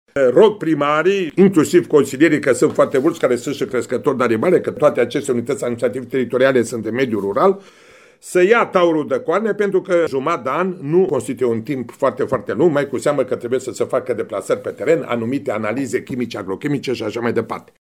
Preşedintele Comisiei pentru Agricultură din cadrul Consiliului Judeţean Timiş, Nicolae Oprea, face apel către primăriile restante să urgenteze lucrările.